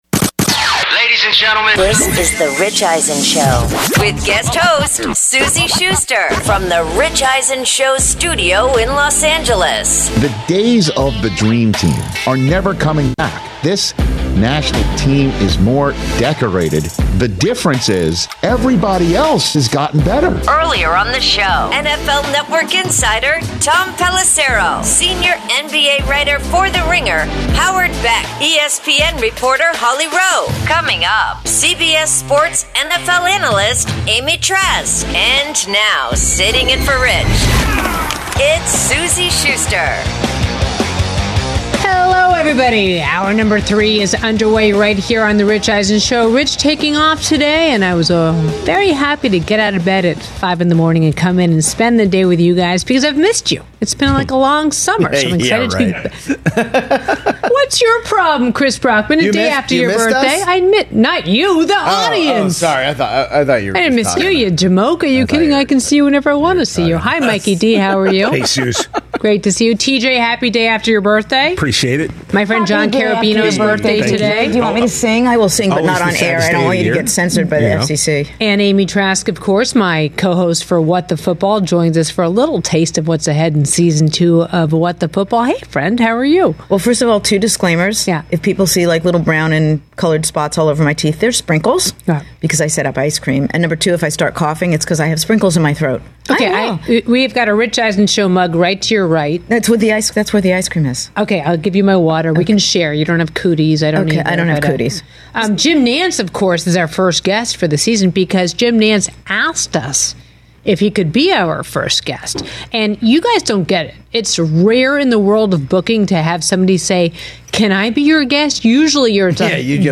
Guest host Suzy Shuster is joined in-studio by CBS Sports NFL Analyst/What the Football podcast co-host Amy Trask who talks Steelers, Chargers, Tom Brady, Bill Belichick, Stefon Diggs, Saquon Barkley, Anthony Richardson, Aaron Rodgers arrival at Jets camp, and more.